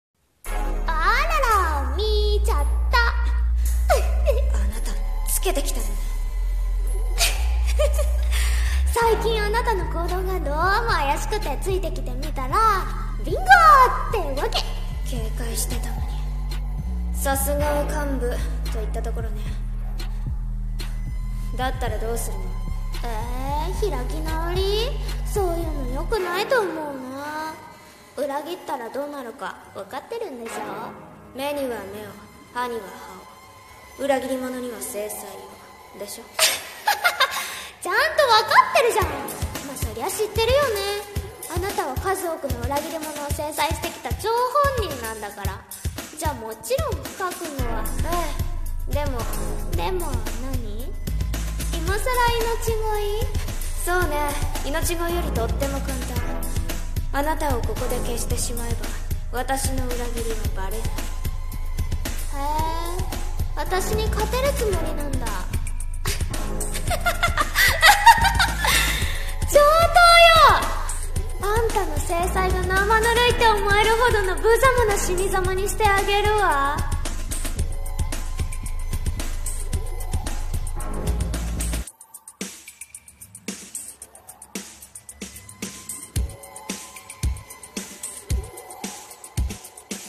【声劇】裏切りには制裁を【掛け合い】